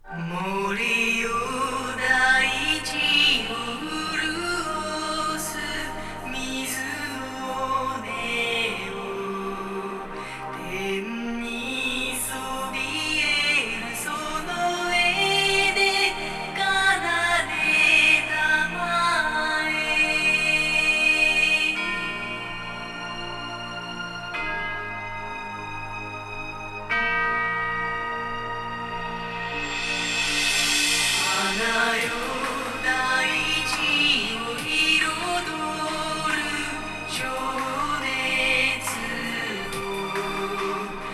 具体的にはEBU R128に基づき、−20 LUFSで正規化してみたのだ。